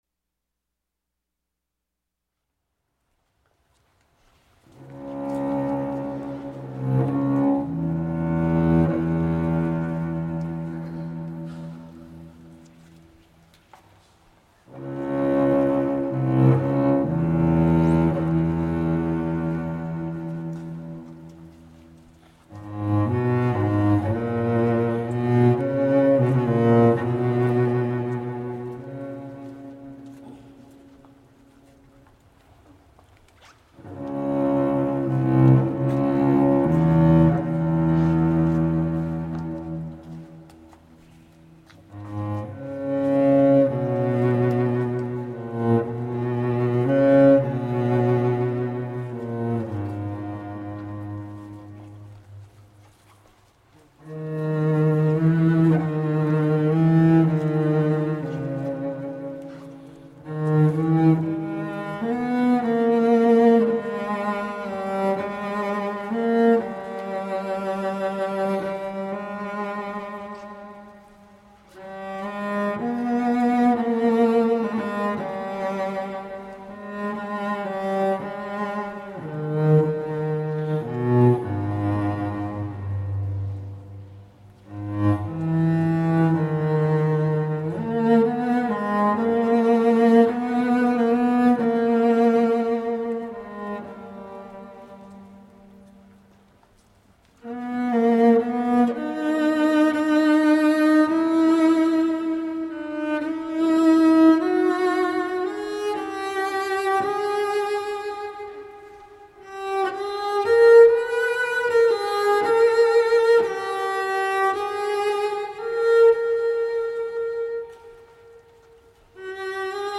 New age meets indian cello.
Contemplative solo cello recorded inside Chartres Cathedral.
Tagged as: New Age, World, Cello, Massage